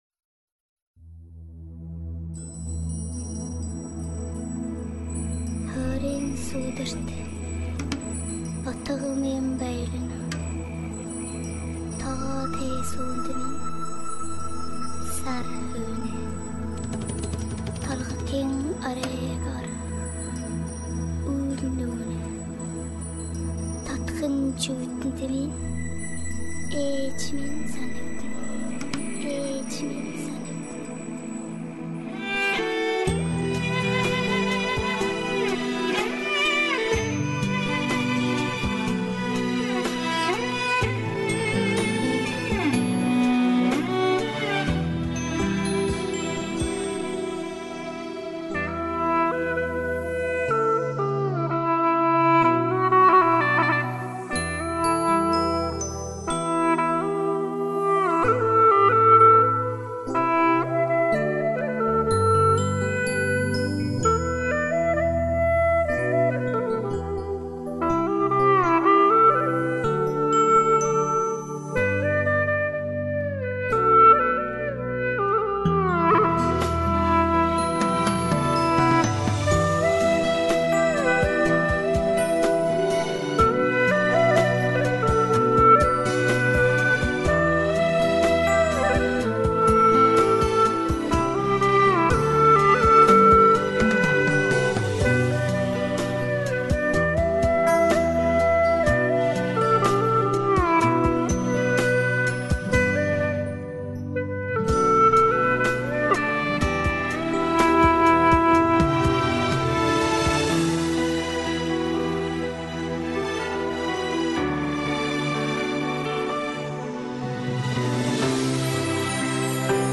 调式 : G 曲类 : 流行
【G调演奏】